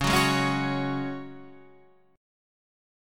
Dbsus4 chord